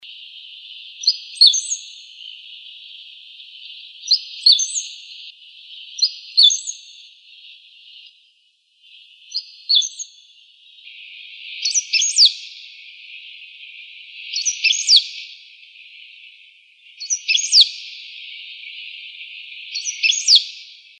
Pampa Finch (Embernagra platensis)
Life Stage: Adult
Location or protected area: Reserva Ecológica Costanera Sur (RECS)
Condition: Wild
Certainty: Recorded vocal